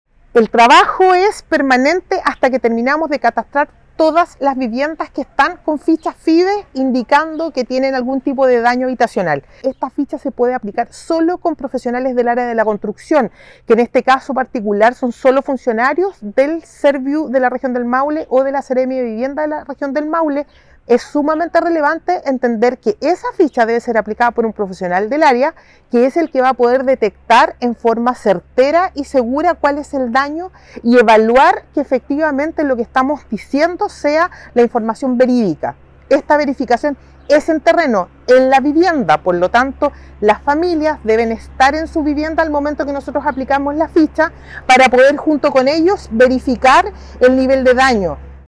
SERVIU-viviendas-danadas_directora-1.mp3